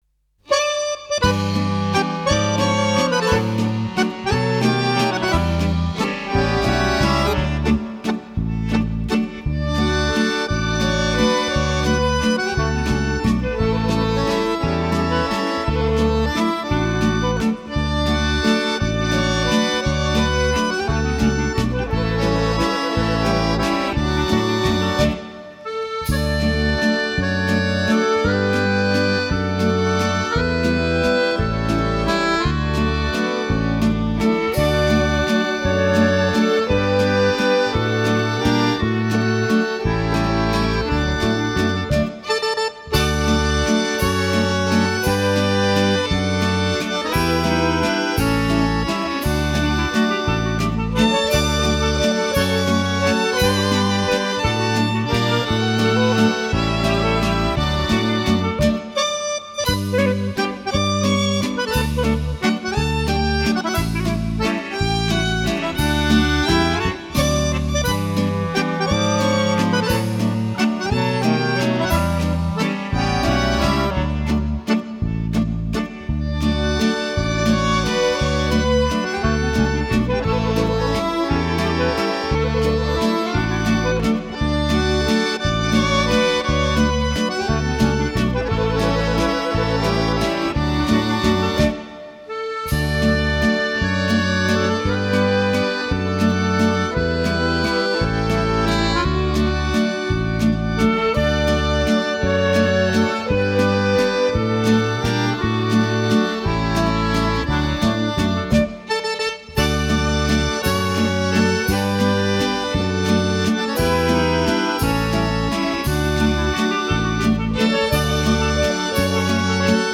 Genre: Easy Listening, Instrumental